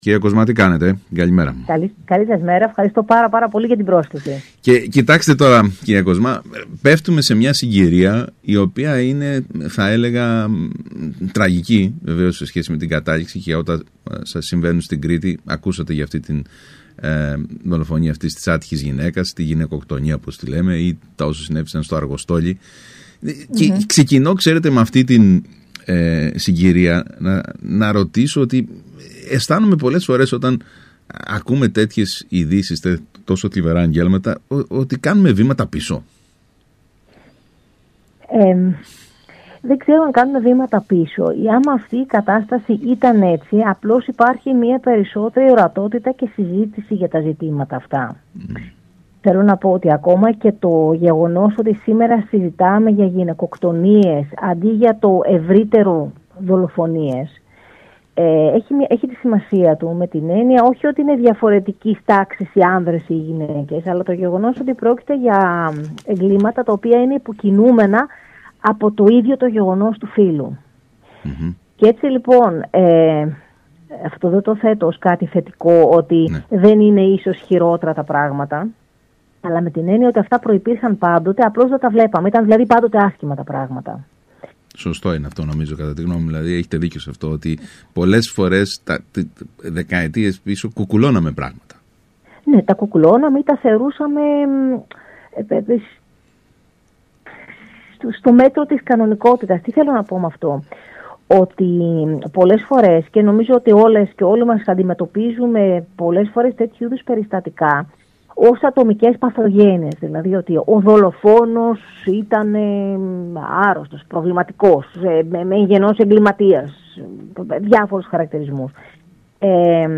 μιλώντας στον ΣΚΑΪ Κρήτης 92,1